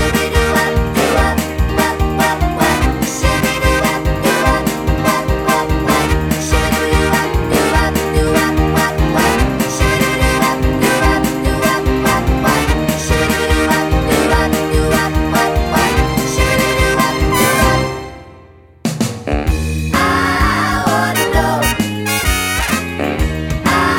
no Backing Vocals Soundtracks 4:18 Buy £1.50